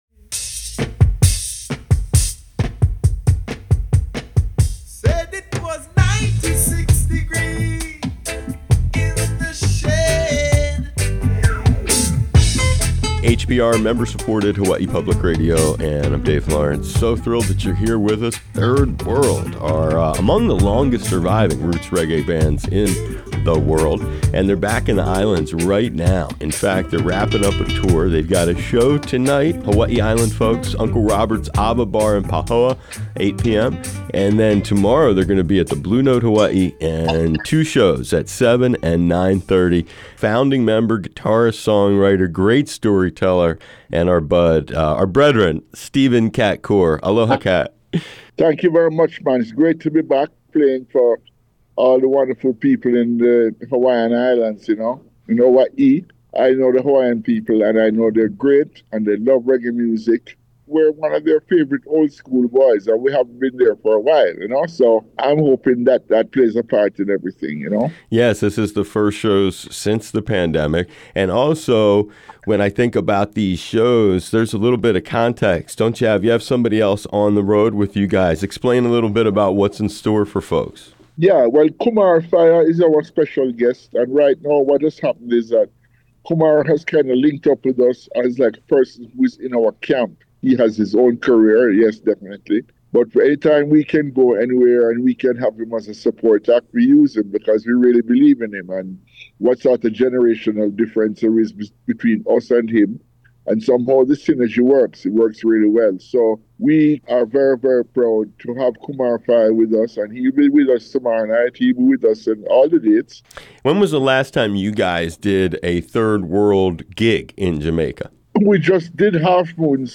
Interview Sampler